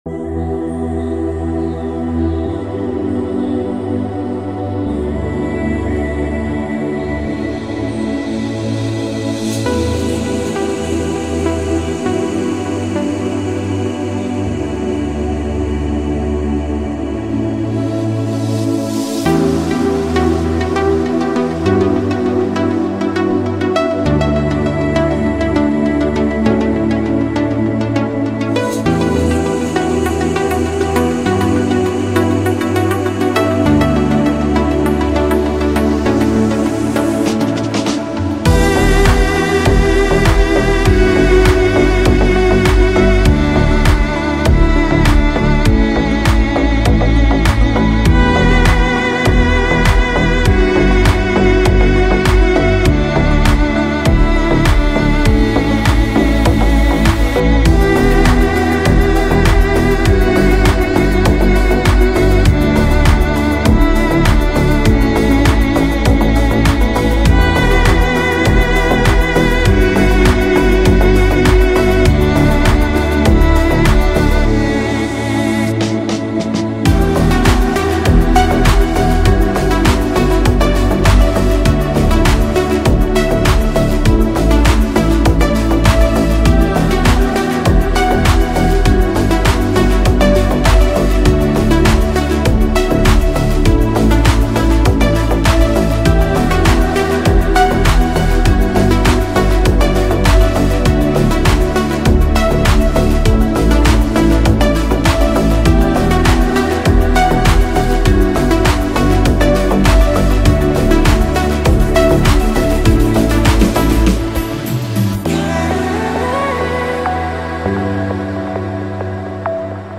Data: 04.11.2024  House Music Hits: 0